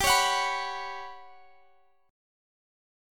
Listen to GM7b5 strummed